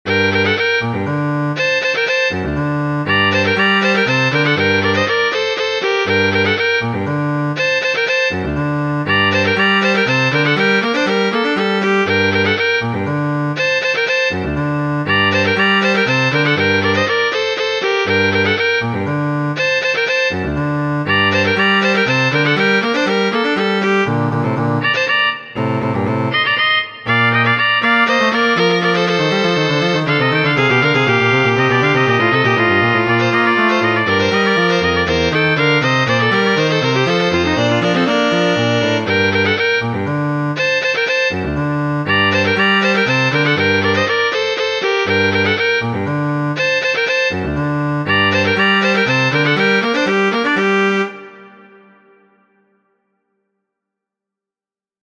S.L. Muziko: El La libro por Ana Magdaleno de J. S. Bach.